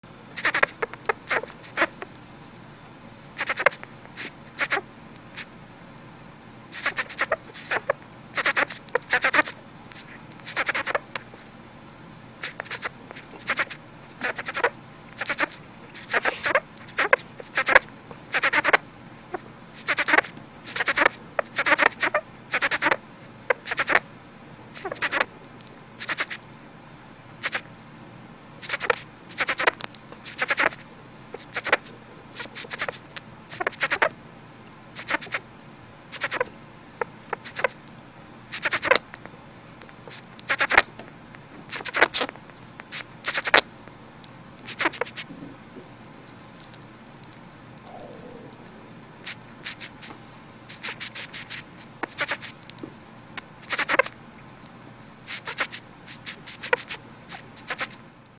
NURSING, SHRIEKING, SINGING
Very terse "TSST-TSST-TSST" and probably jerking of the body.